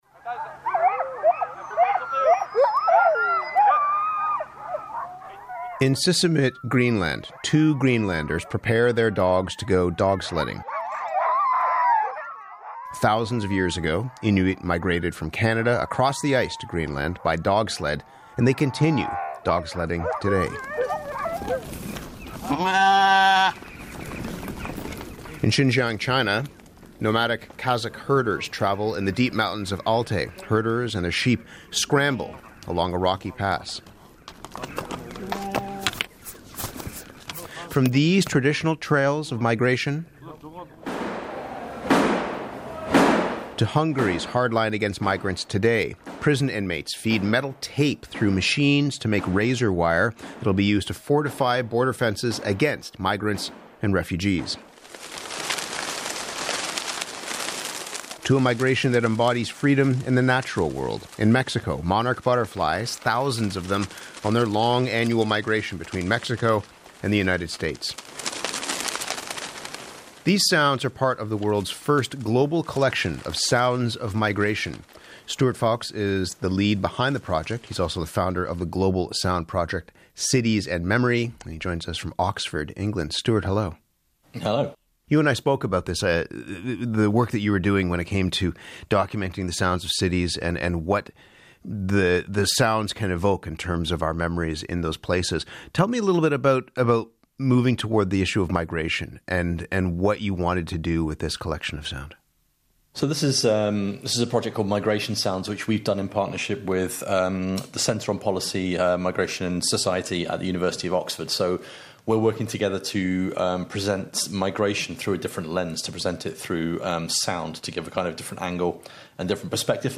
Migration Sounds feature on CBC Radio (Canadian national radio), on The Current show presented by Matt Galloway.